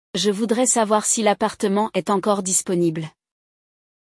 Você vai acompanhar um diálogo realista entre um locatário e um proprietário, absorvendo vocabulário essencial e estruturas gramaticais úteis para negociar um aluguel em francês.
Escuta ativa e aprendizado natural: Você ouvirá o diálogo primeiro sem tradução, para exercitar sua compreensão auditiva.